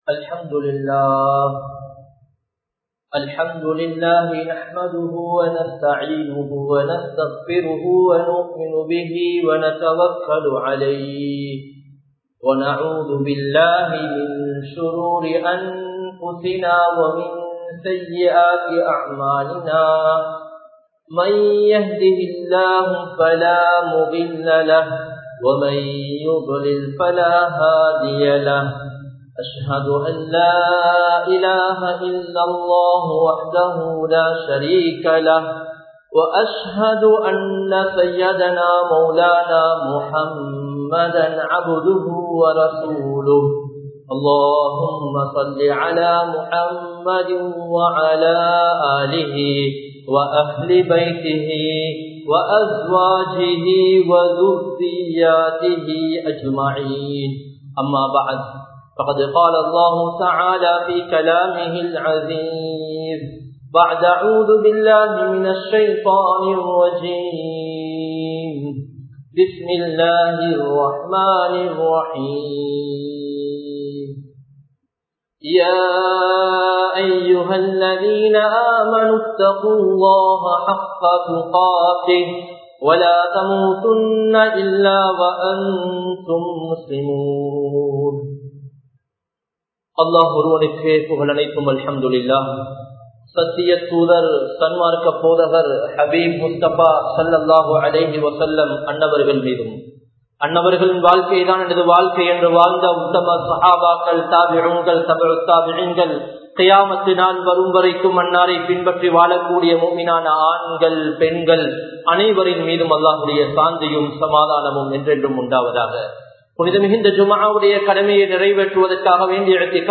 Nabi(SAW)Avarhalin Valimuraiel Veattri (நபி(ஸல்) அவர்களின் வழிமுறையில் வெற்றி) | Audio Bayans | All Ceylon Muslim Youth Community | Addalaichenai
Gothatuwa, Jumua Masjidh 2018-03-23 Tamil Download